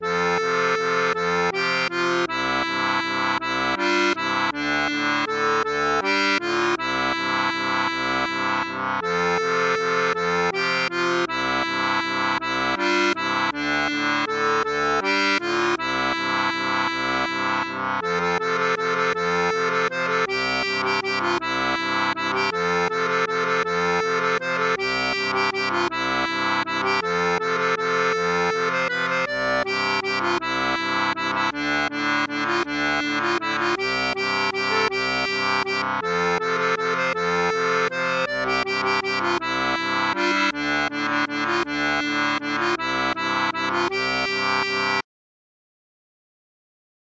Version Chant
Chanson française